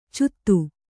♪ cuttu